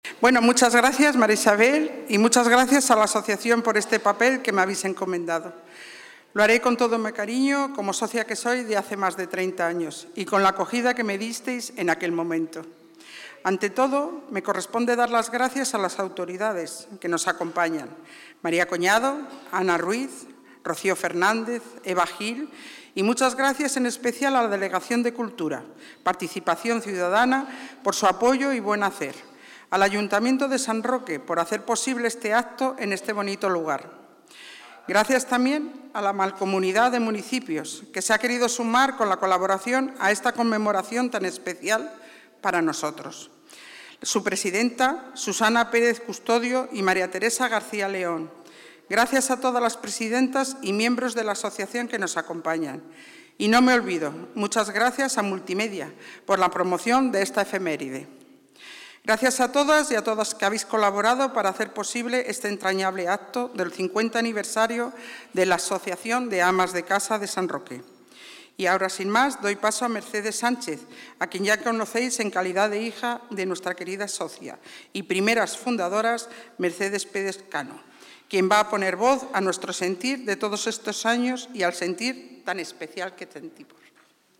ANIVERSARIO CONSTANCIA (TOTAL USUARIA) 5 MARZO 2026.mp3